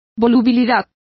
Complete with pronunciation of the translation of fickleness.